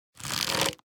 Minecraft Version Minecraft Version snapshot Latest Release | Latest Snapshot snapshot / assets / minecraft / sounds / item / crossbow / quick_charge / quick2_1.ogg Compare With Compare With Latest Release | Latest Snapshot